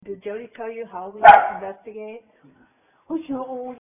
house2dogbark.mp3